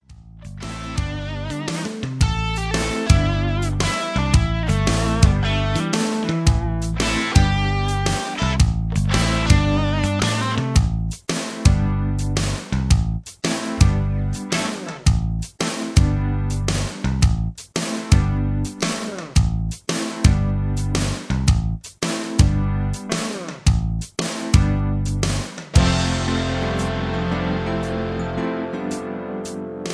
Just Plain & Simply "GREAT MUSIC" (No Lyrics).
mp3 backing tracks